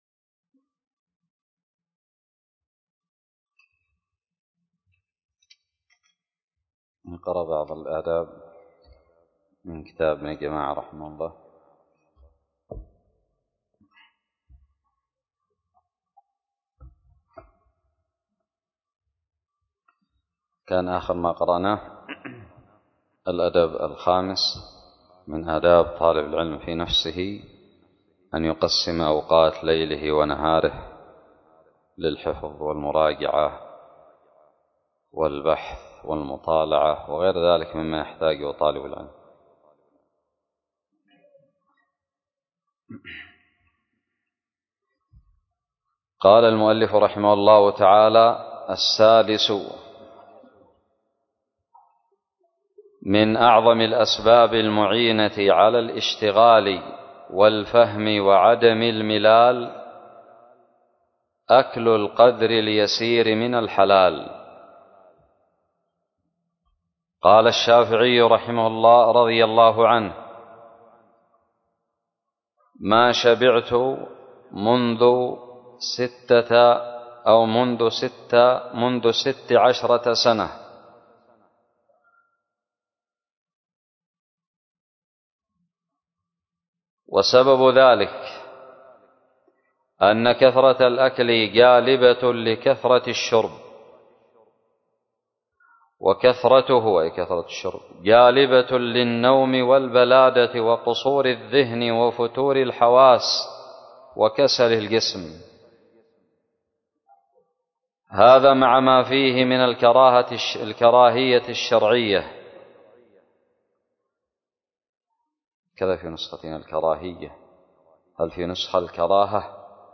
الدرس الرابع والعشرون من شرح كتاب تذكرة السامع والمتكلم 1444هـ
ألقيت بدار الحديث السلفية للعلوم الشرعية بالضالع